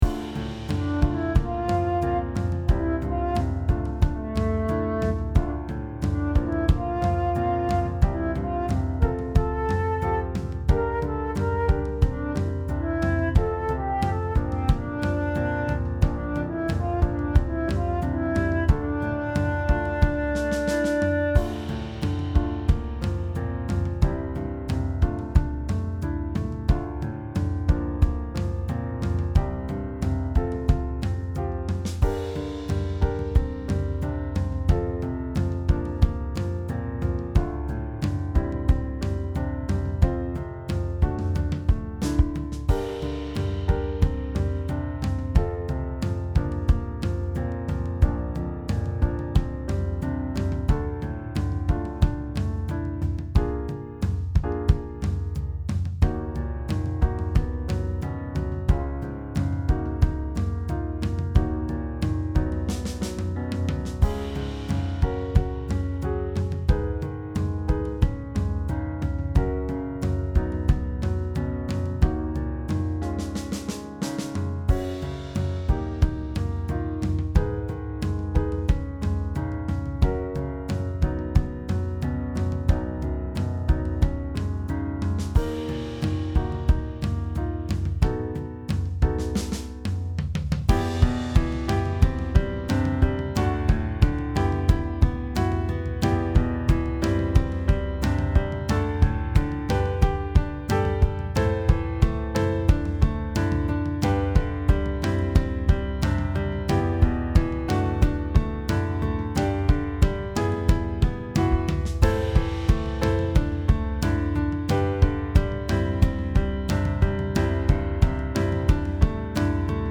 Heer_U_bent_mijn_leven band, v.a. 21 sec.